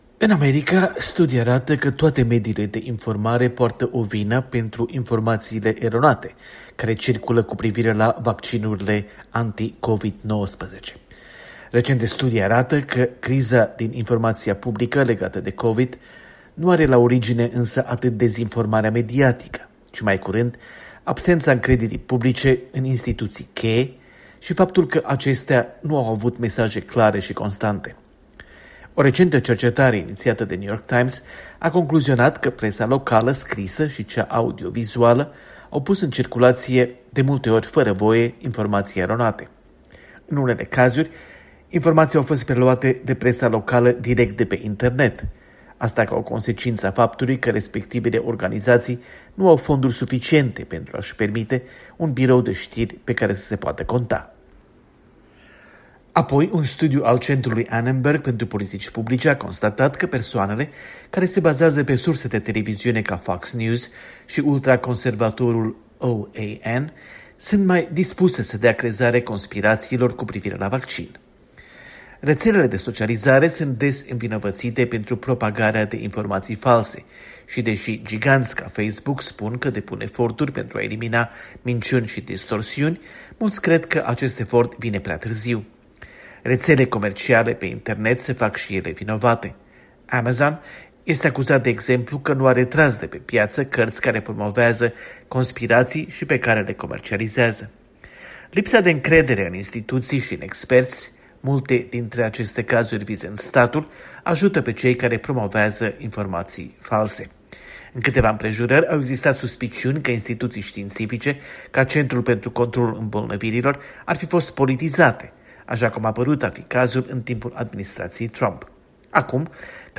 Corespondența de la Washington